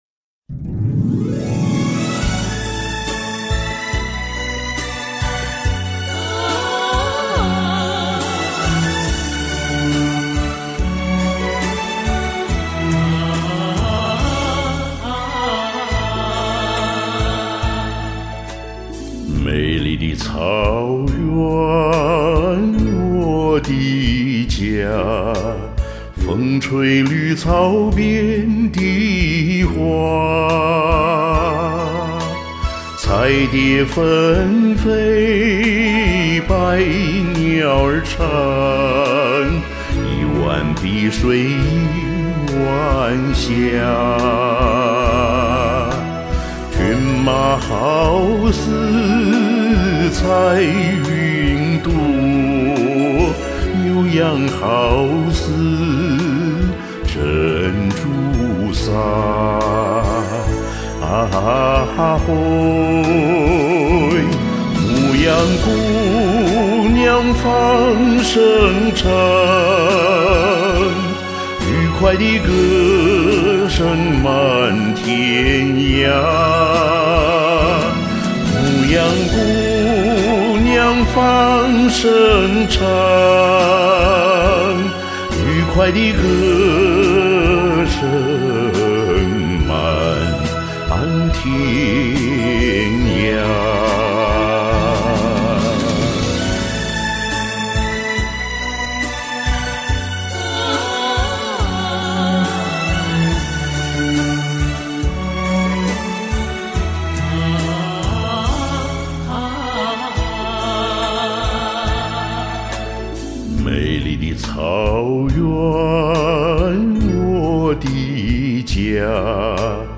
染上新冠两个多礼拜了，咽喉一直不清爽，说话还有很重鼻音，昨天感觉好些，赶紧录两首：）